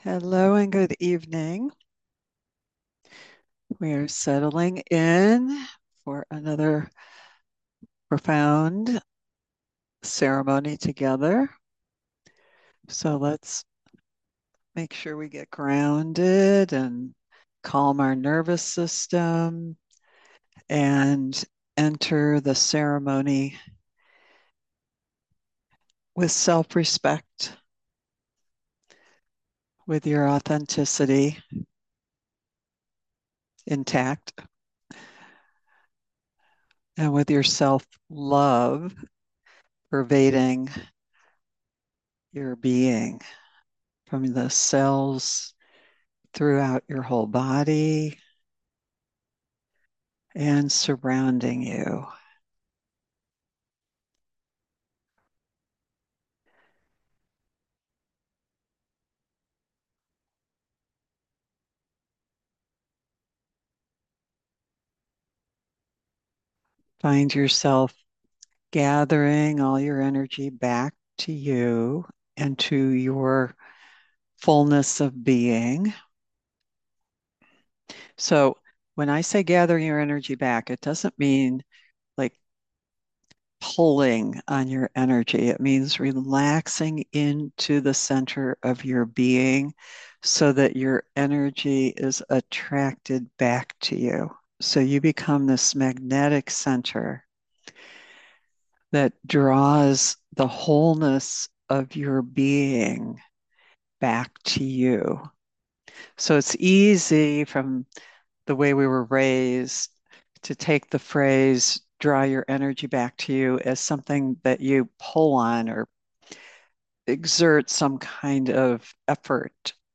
Genre: Guided Meditation.